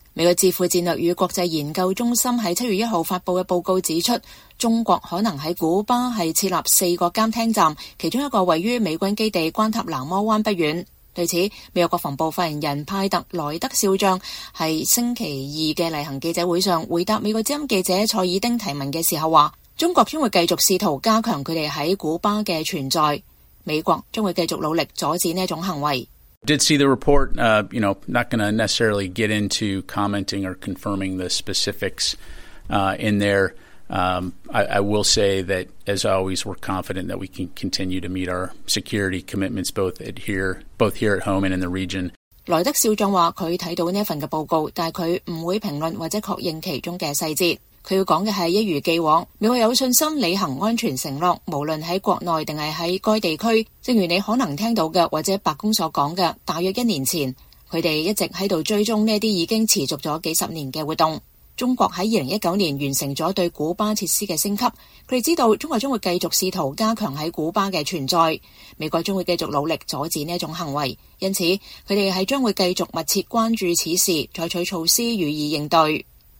美國國防部發言人派特.萊德少將